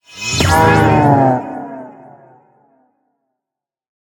Minecraft Version Minecraft Version 1.21.4 Latest Release | Latest Snapshot 1.21.4 / assets / minecraft / sounds / mob / mooshroom / convert1.ogg Compare With Compare With Latest Release | Latest Snapshot